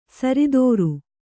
♪ saridōru